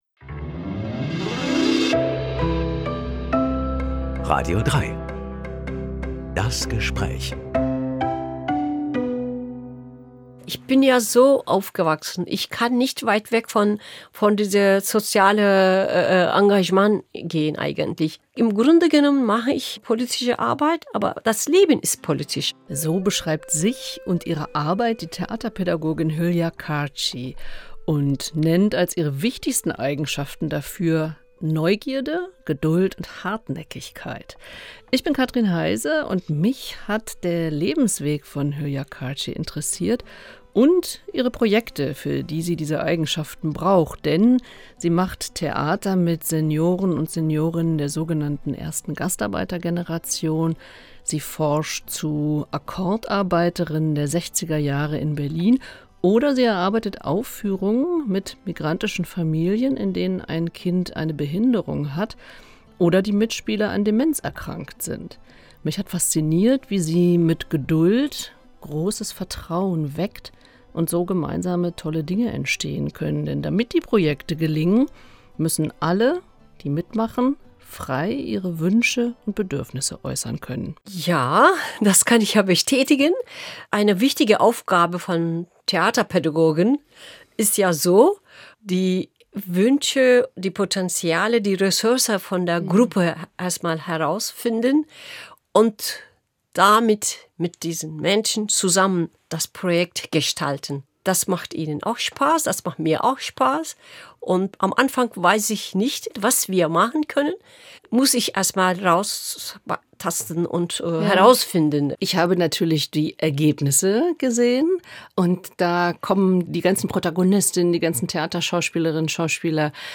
Das Gespräch Podcast